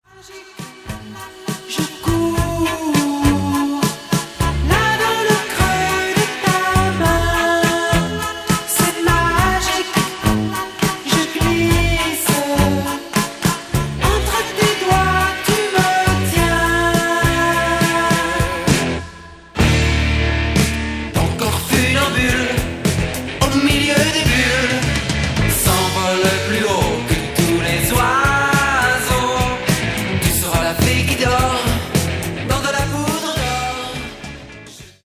Genere:   Disco